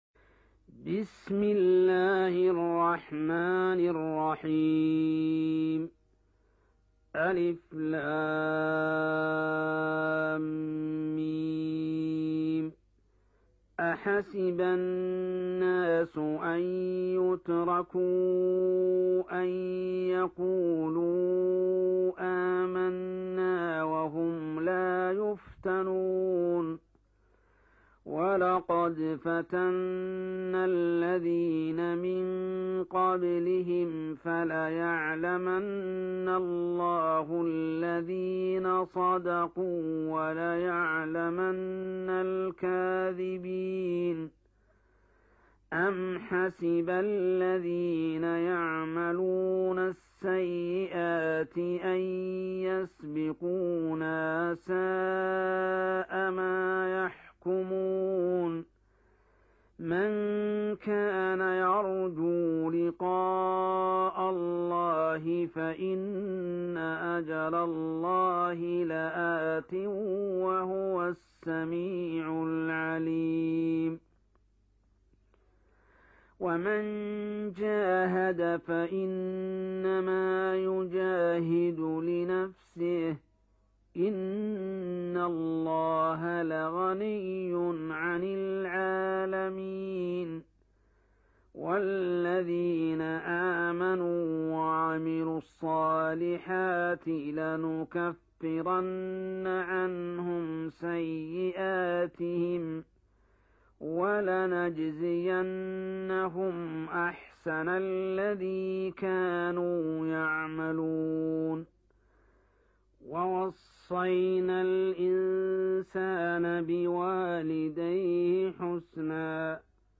Surah Repeating تكرار السورة Download Surah حمّل السورة Reciting Murattalah Audio for 29. Surah Al-'Ankab�t سورة العنكبوت N.B *Surah Includes Al-Basmalah Reciters Sequents تتابع التلاوات Reciters Repeats تكرار التلاوات